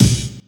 dong.wav